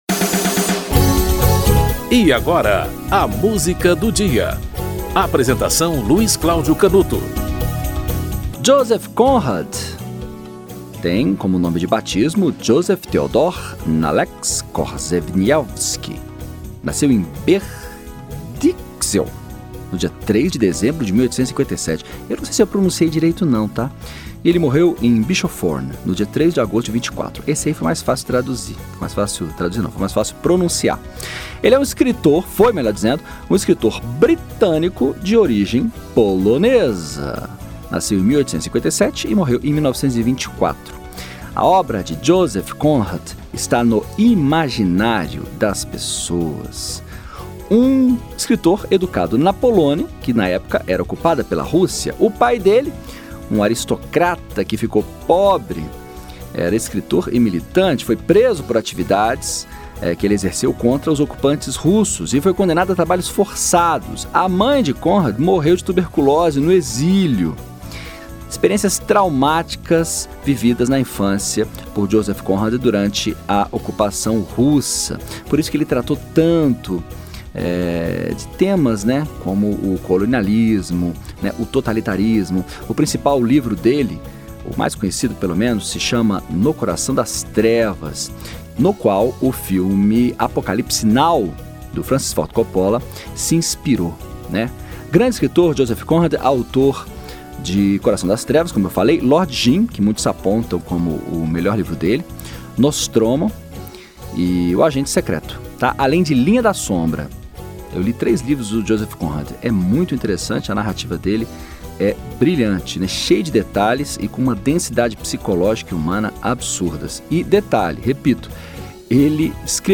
Filarmônica de Berlim - A Cavalgada das Valquírias (Richard Wagner)